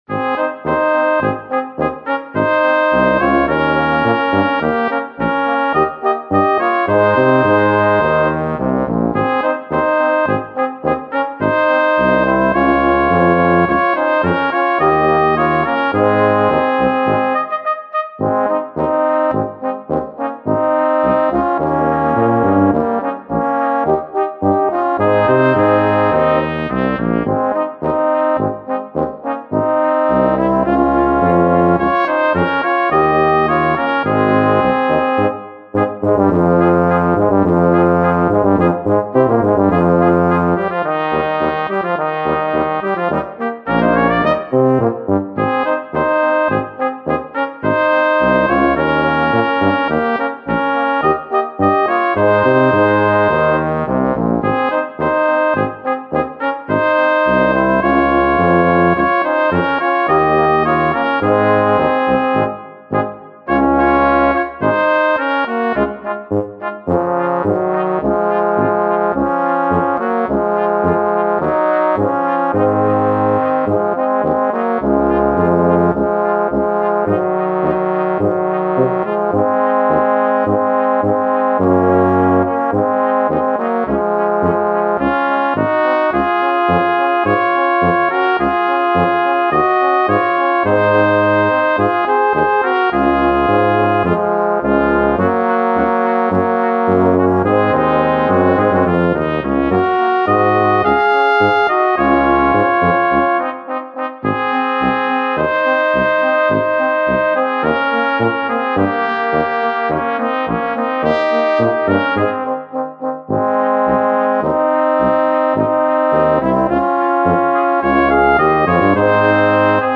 4er Besetzung
Polka